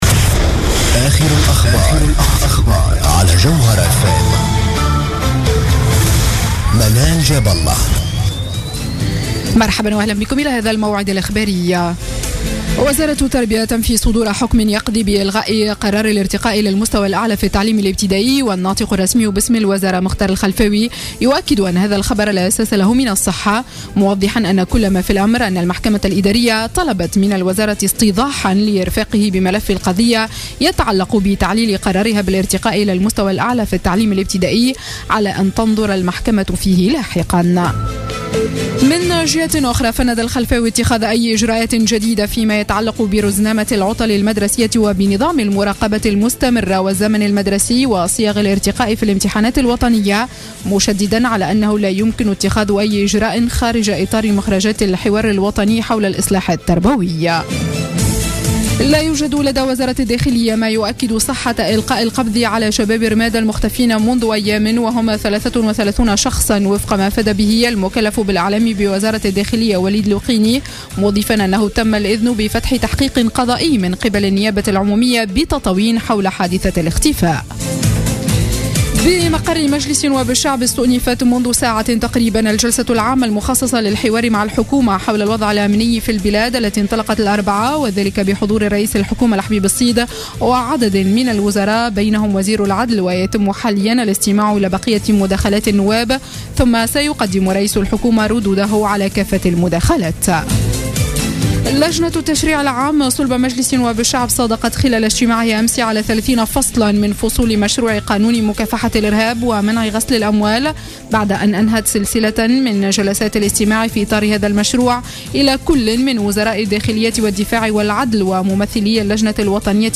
نشرة أخبار منتصف الليل ليوم الجمعة 10 جويلية 2015